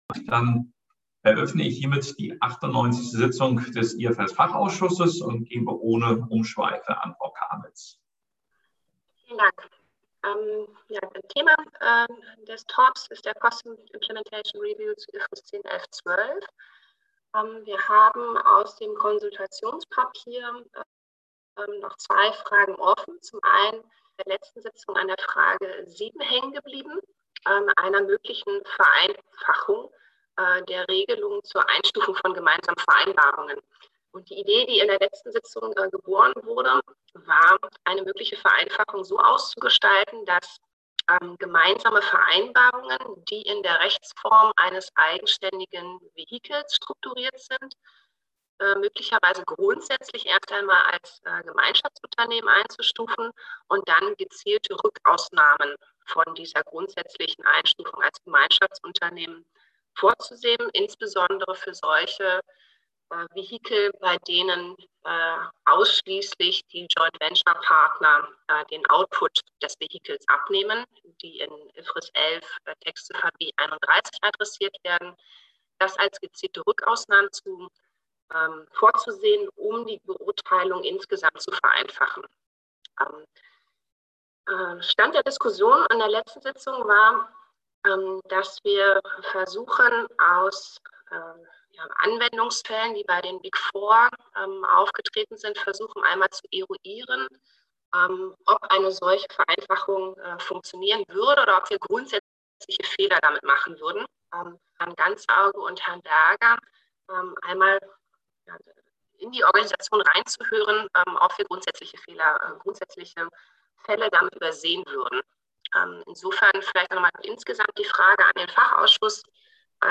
98. Sitzung IFRS-FA • DRSC Website